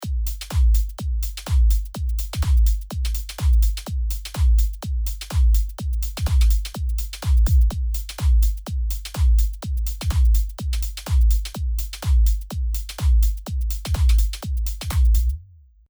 一応ブロック3でも聞いておきましょう。
フィルターがネガティブ方向へ動いた際のローの雰囲気じとかいいですね～。